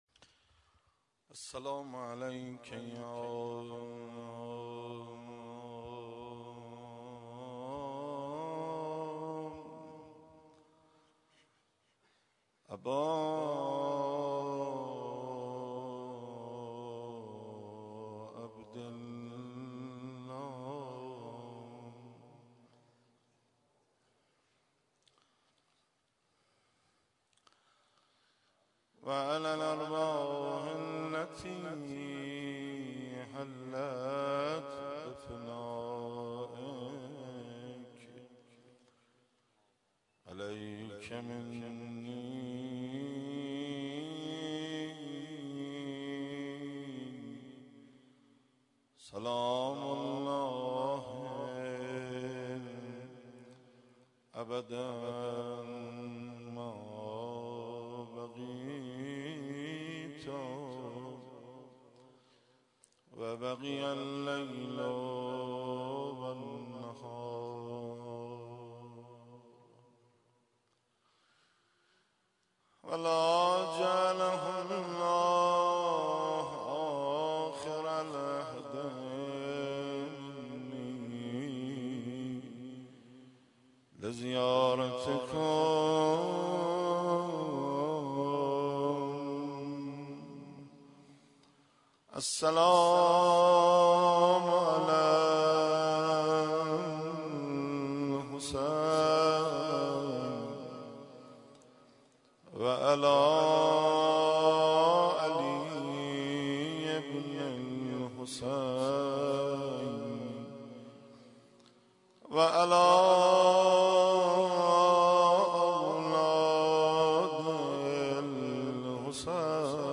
مداحی
و همسر و عروسش با حضور اقشار مختلف مردم و هیئات مذهبی در مسجد امام حسین (ع) برگزار شد.
مداحی و روضه خوانی کرد